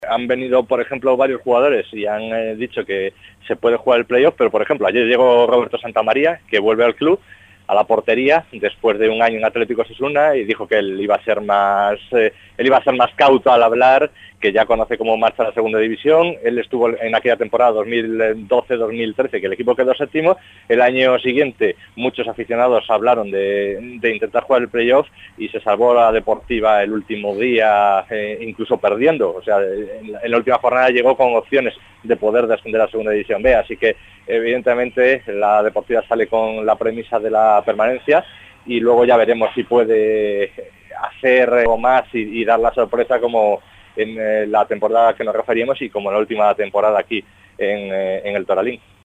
PERIODISTA – OBJETIVOS PONFERRADINA